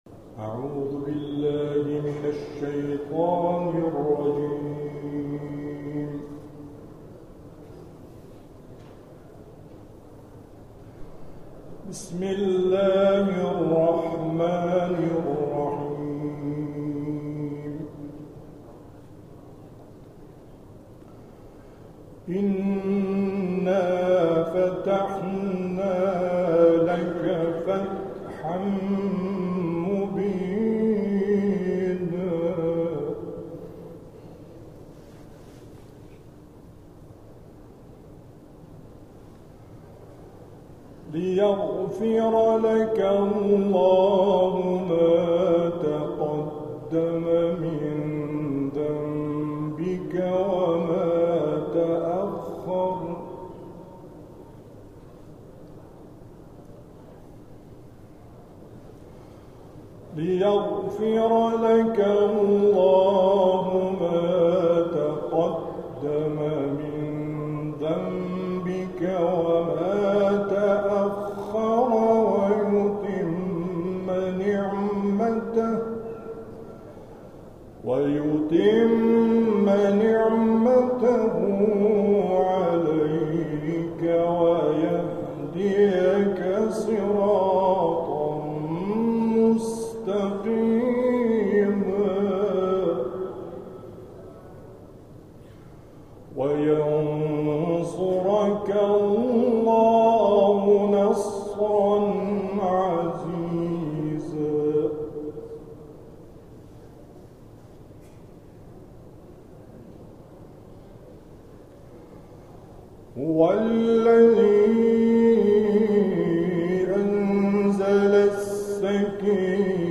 این کرسی‌های تلاوت، هر هفته در روز‌های دوشنبه، بلافاصله پس از نماز ظهر و عصر در مسجد دانشگاه امام صادق(ع) برگزار می‌شود و اساتید قرآنی با حضور در این کرسی‌ها به تلاوت آیاتی از کلام الله مجید می‌پردازند.
جلسات و محافل ، جلسه قرآن ، کرسی تلاوت ، دانشگاه امام صادق